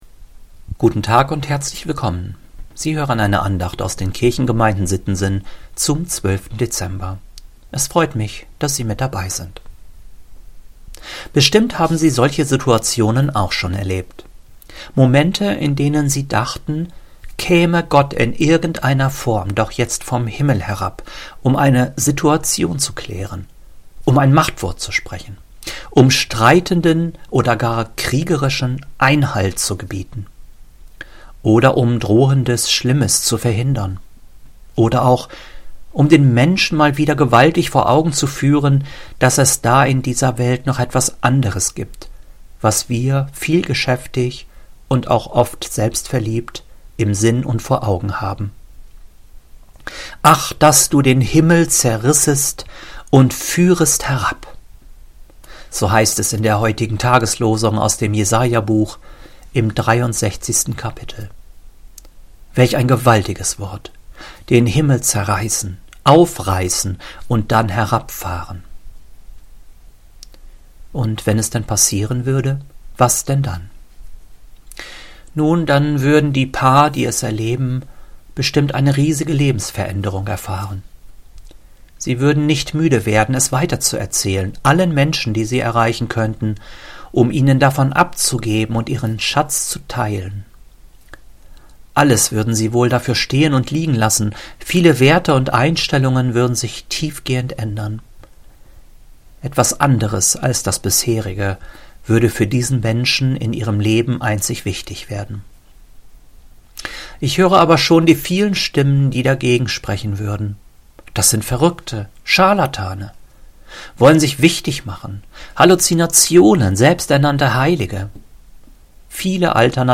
Audio-Dateien der Andachten (Herrnhuter Losungen)